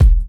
Kick_102.wav